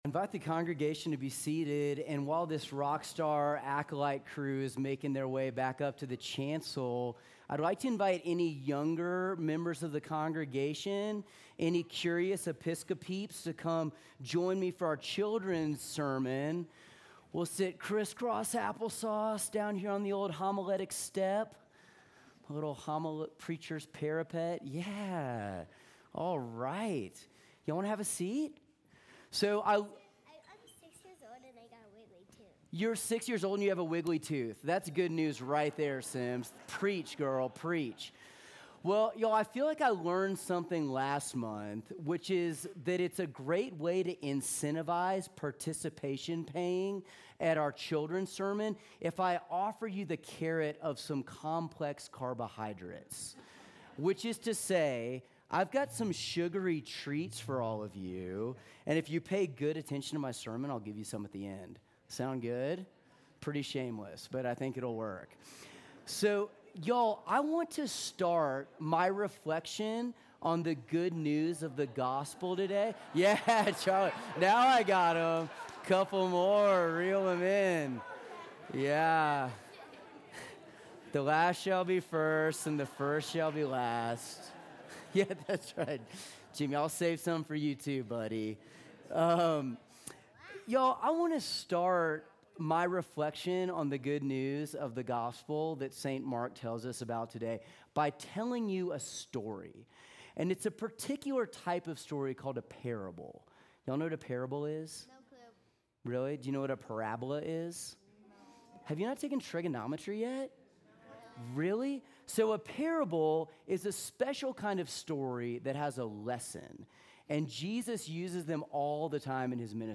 Sermons
October Family Service